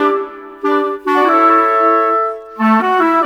Rock-Pop 06 Winds 03.wav